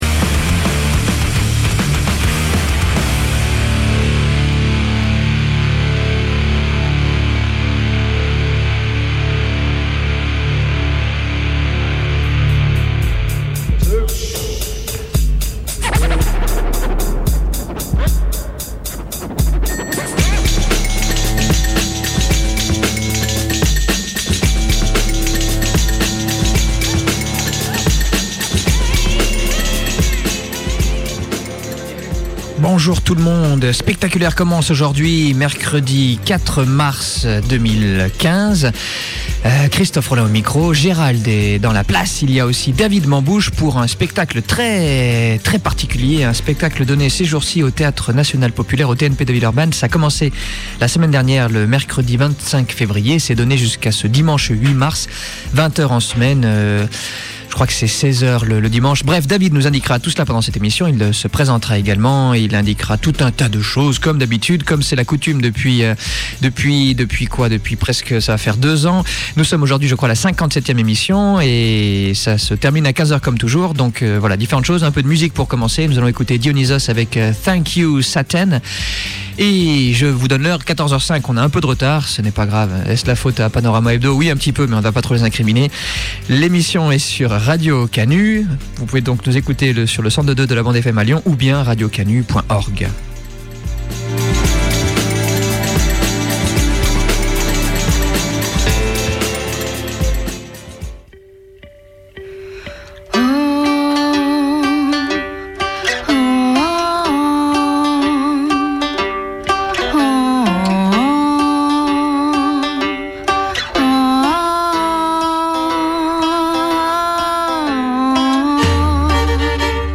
émission à caractère culturel (un peu mais pas trop quand même) les mercredi de 14h à 15h
(problème : seules les trois premières minutes de l’émission sont disponibles ci-dessous… – mais promis : on va chercher, au plus vite, à mettre l’intégralité en ligne !)